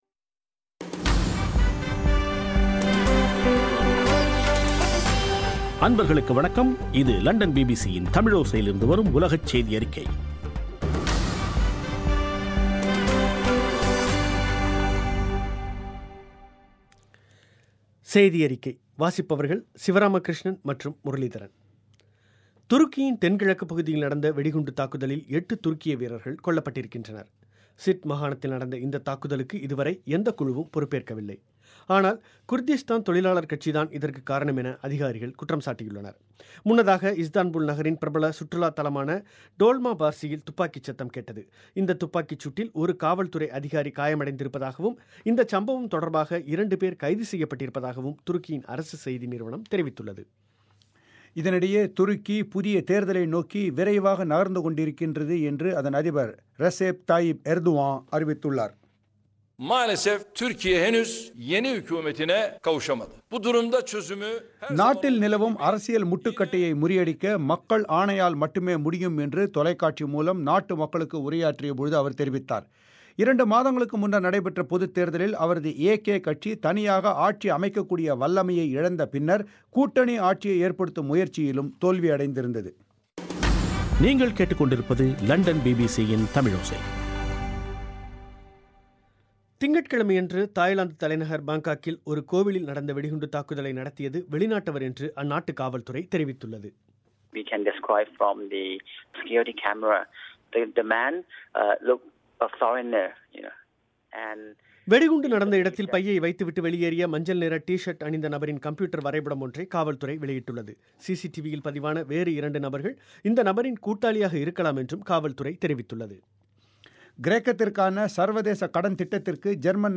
ஆகஸ்ட் 19, 2015 பிபிசி தமிழோசையின் உலகச் செய்திகள்